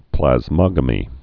(plăz-mŏgə-mē)